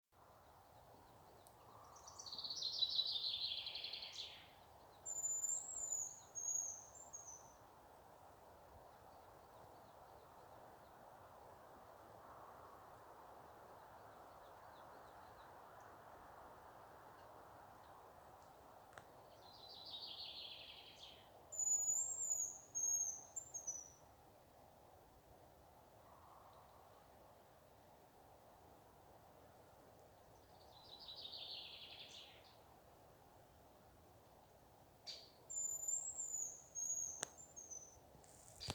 Mežirbe, Bonasa bonasia
StatussDzied ligzdošanai piemērotā biotopā (D)